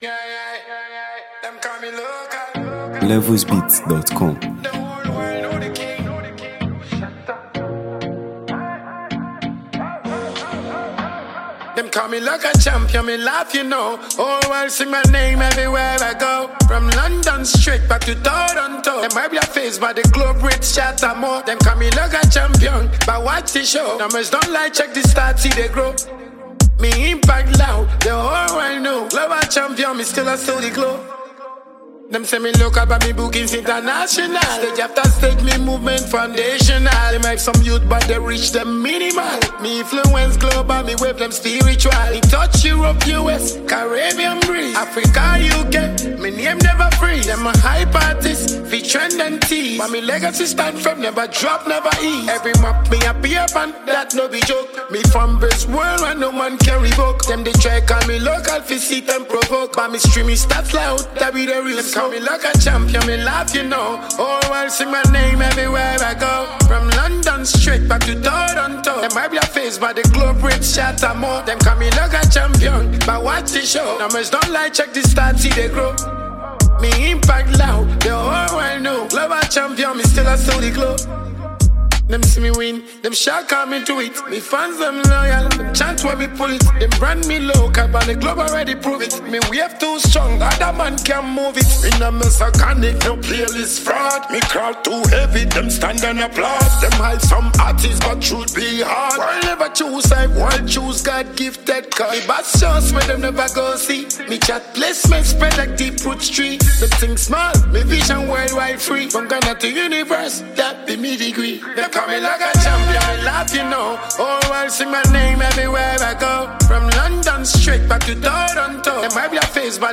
Ghana Music 2025 2:43